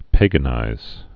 (pāgə-nīz)